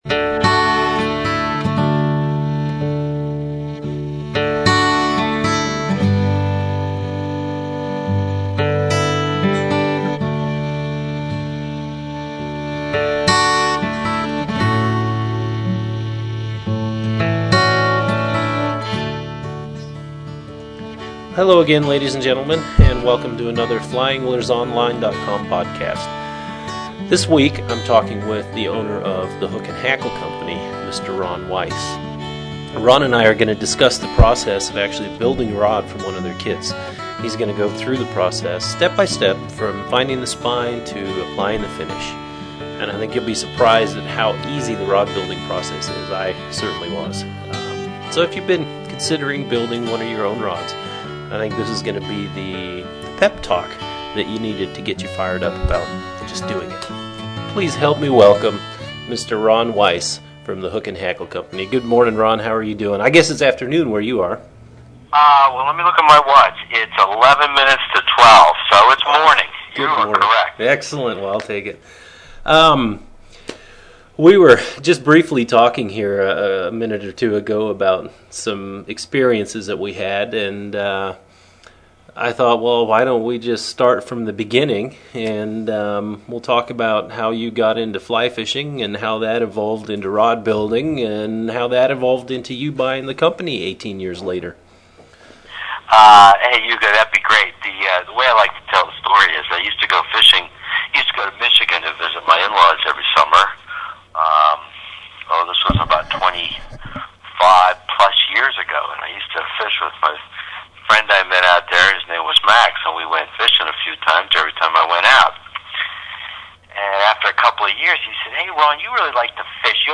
Podcast #3 - HOOK AND HACKLE INTERVIEW